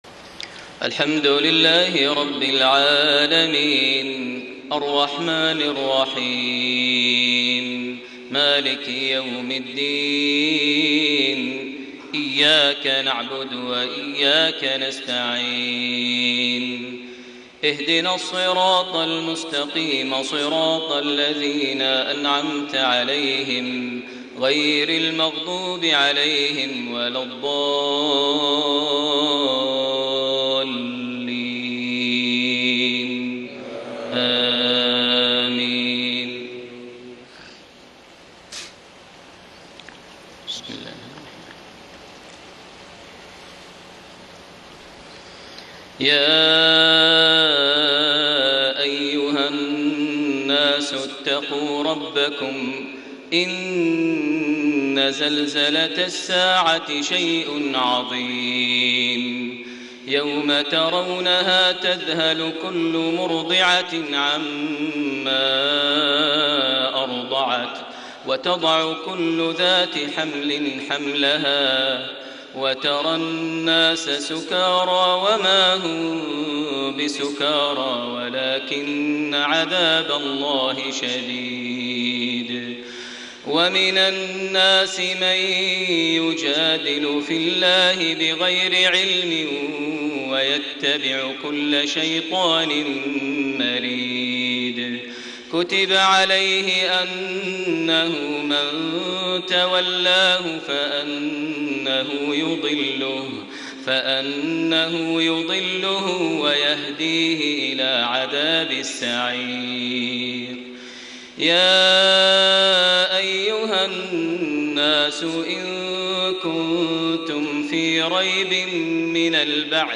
صلاة الفجر 5 شعبان 1433هـ فواتح سورة الحج 1-14 > 1433 هـ > الفروض - تلاوات ماهر المعيقلي